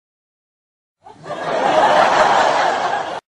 laughing 1